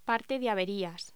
Locución: Parte de averías
voz